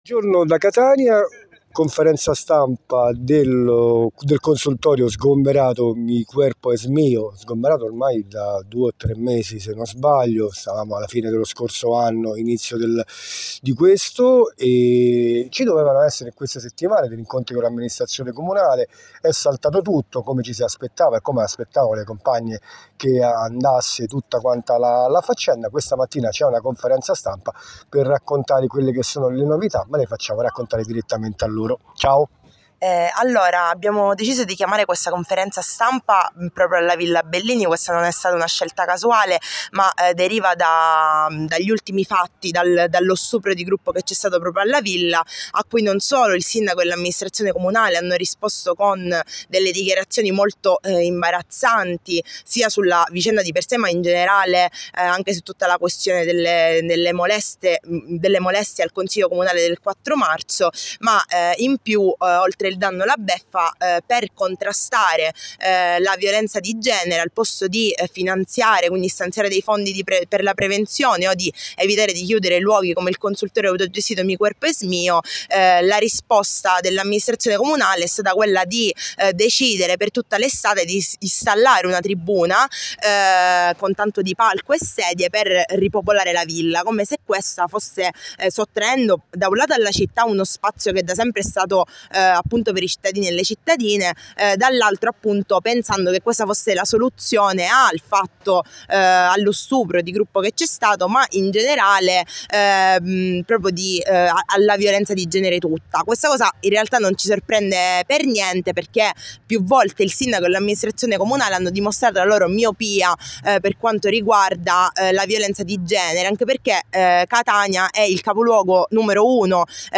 Catania, conferenza stampa del consultorio sgomberato